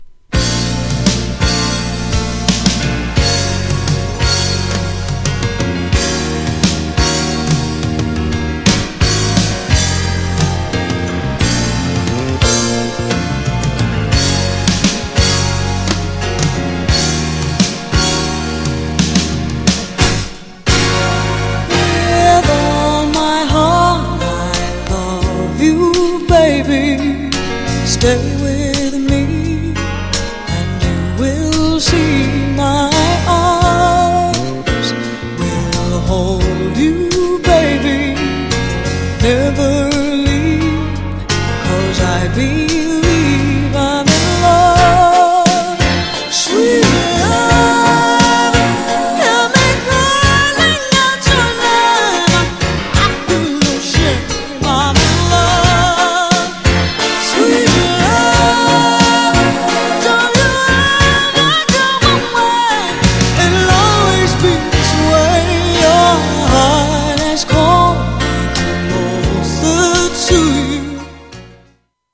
美しい旋律とソウルフルなボーカルにうっとり。
GENRE Dance Classic
BPM 86〜90BPM